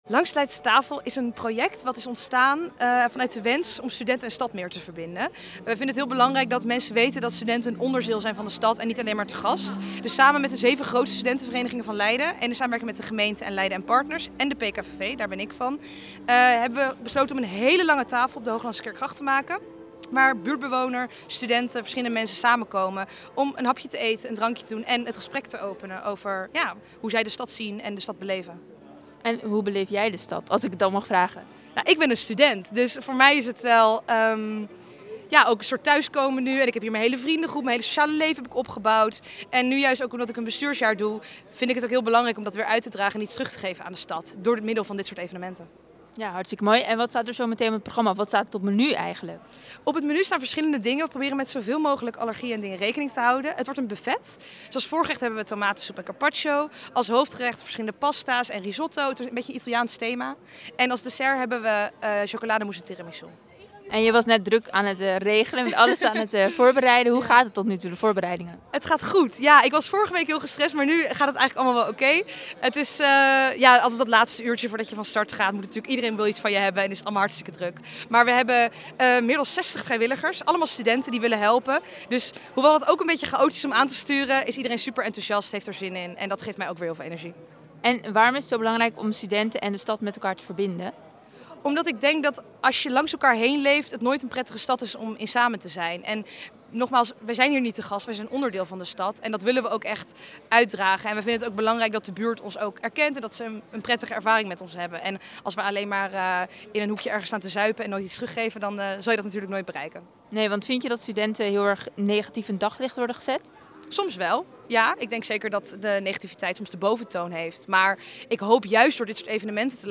in gesprek met verslaggever